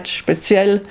Speziell ‘spécial’ (Tyrolien)